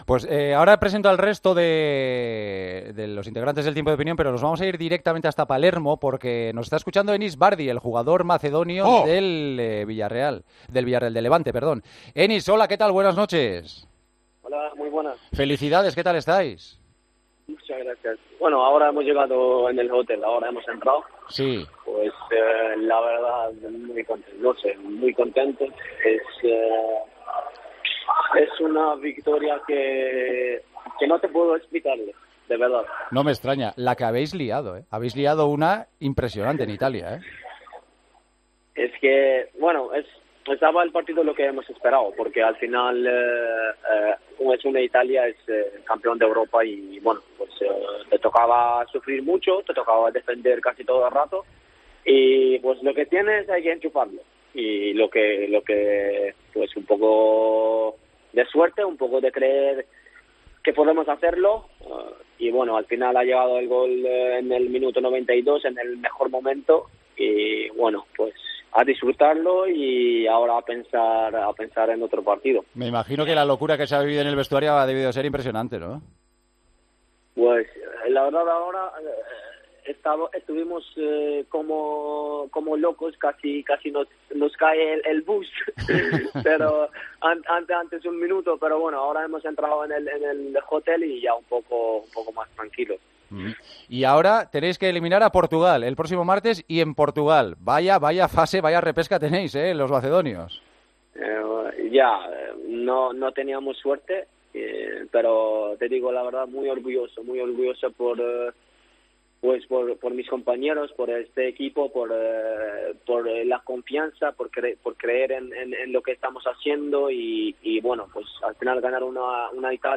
El futbolista de la selección de Macedonia ha atendido a El Partidazo de COPE tras eliminar a Italia en la repesca. "Si ganamos a Portugal, que me den el trofeo ya", ha bromeado.